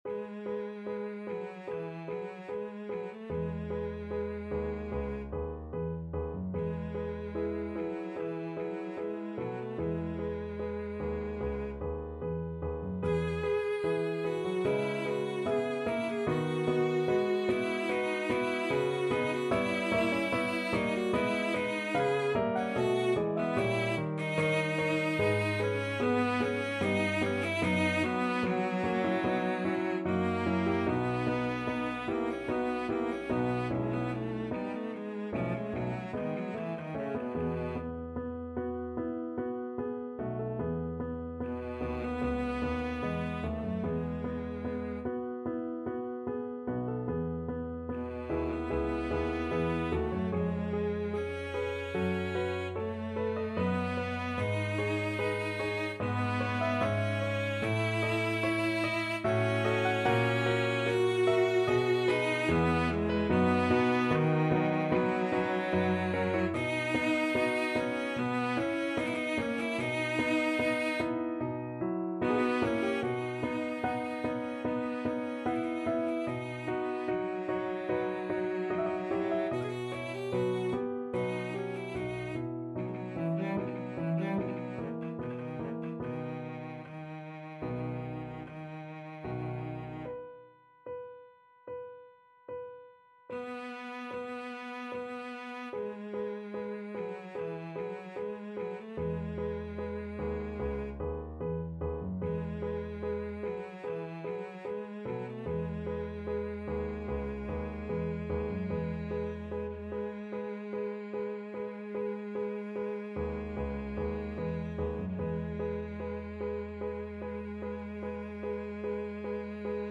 E major (Sounding Pitch) (View more E major Music for Cello )
4/4 (View more 4/4 Music)
~ = 74 Moderato
Cello  (View more Intermediate Cello Music)
Classical (View more Classical Cello Music)